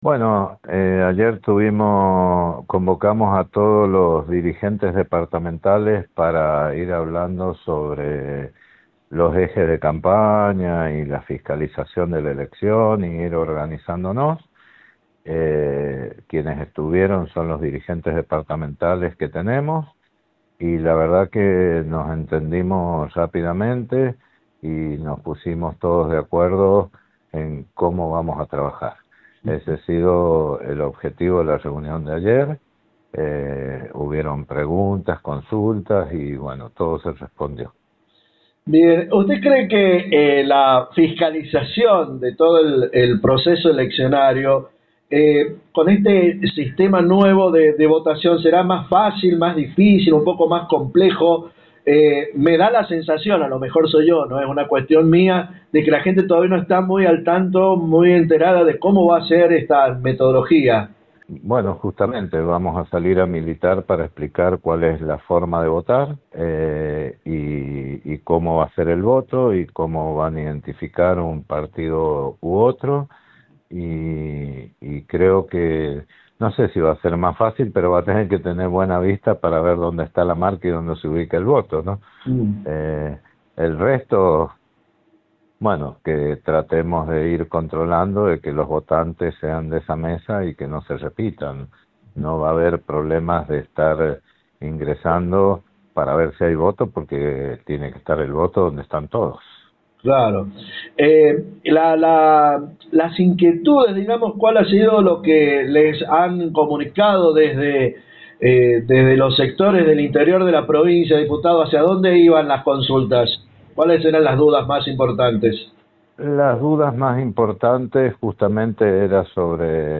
El diputado Nacional Jose Peluc, charló con Radio Genesis,para referirse a la reunión de dirigentes provinciales y departamentales a los efectos de abordar temas vinculados al inicio de la campaña legislativas 2025.